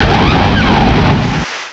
pokeemerald / sound / direct_sound_samples / cries / torterra.aif